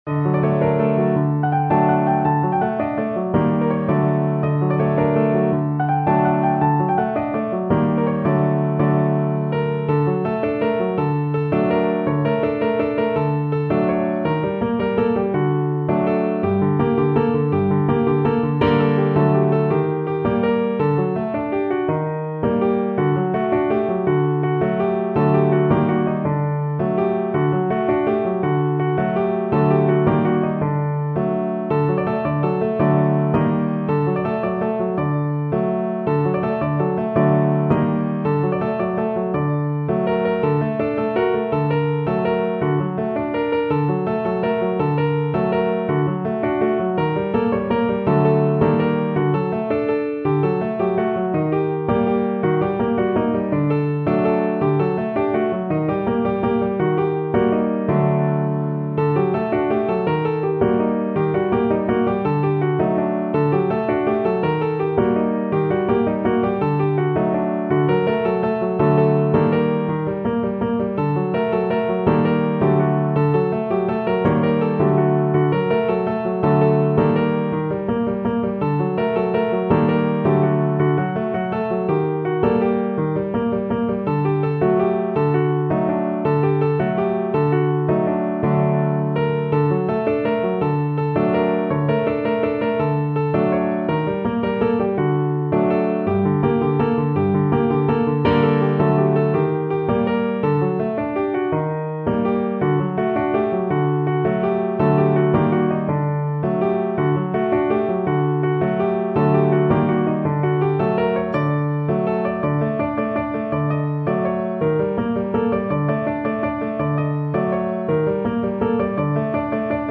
از می بمل ماژور به ر ماژور انتقال داده شد